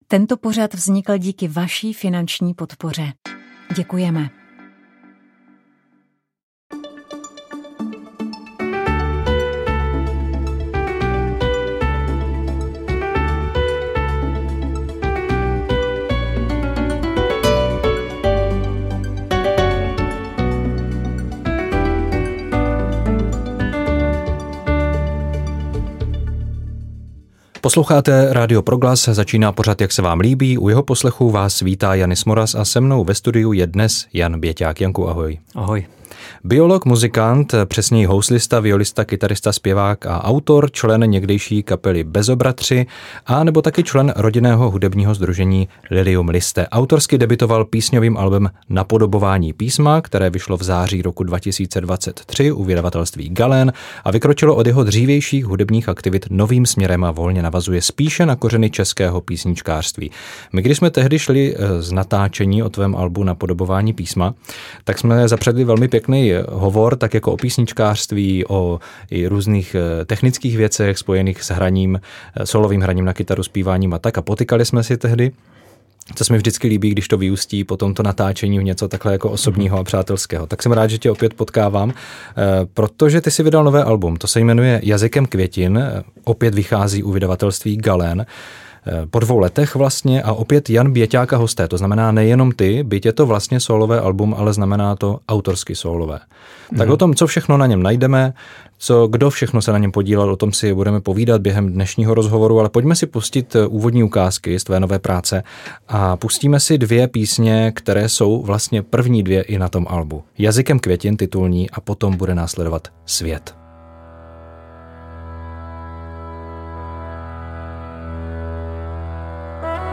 Rozhovor s kapelou Garifuna Collective, kterou tvoří právě příslušníci této národnostní skupiny, jsme natočili na Folkových prázdninách v Náměšti nad Oslavou.